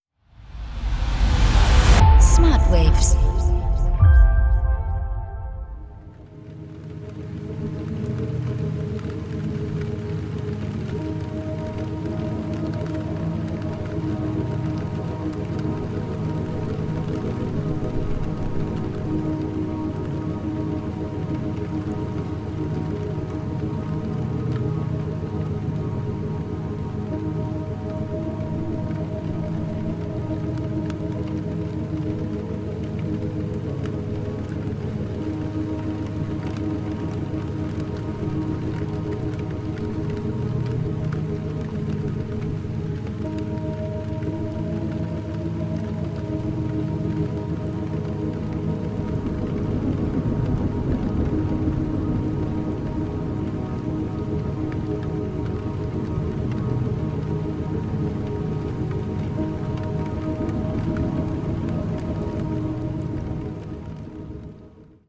entspannende und schwebende Umgebungsgeräusche
• Methode: Binaurale Beats